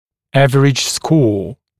[‘ævərɪʤ skɔː][‘эвэридж ско:]средний балл